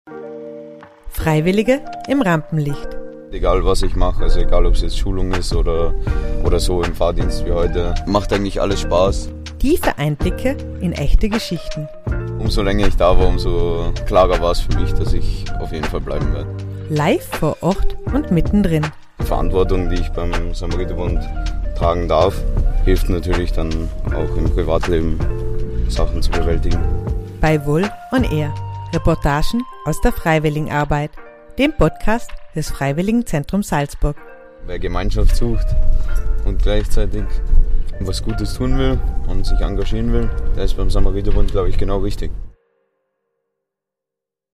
direkt vor Ort, mit viel Interaktion und spannenden Gesprächen.